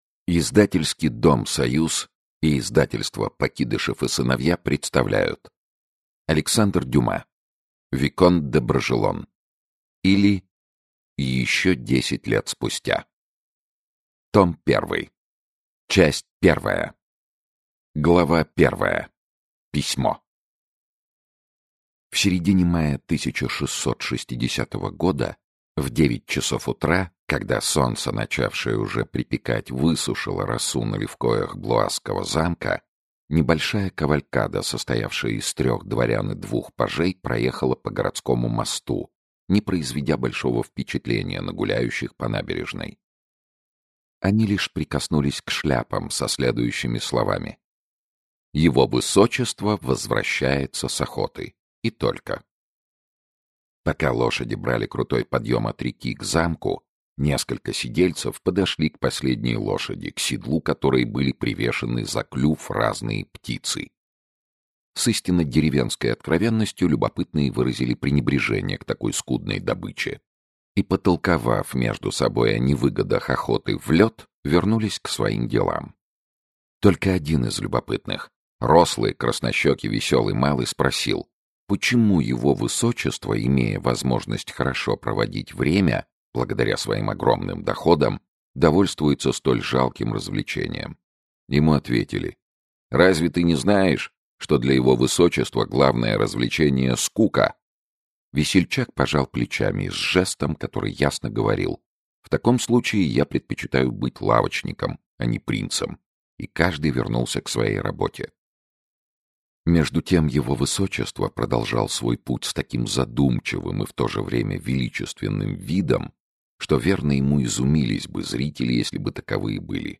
Аудиокнига Виконт де Бражелон. Том первый. Часть I | Библиотека аудиокниг